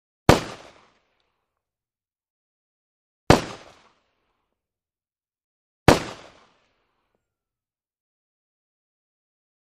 .45 Colt: Single Shot ( 3x ); Three Sharp, Loud, Single Shots With Medium Long Echo. Close Perspective. Gunshots.